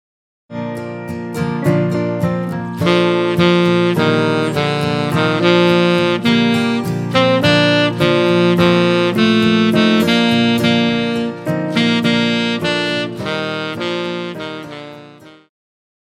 流行
次中音萨克斯风
乐团
演奏曲
摇滚,乡村音乐
仅伴奏
没有主奏
有节拍器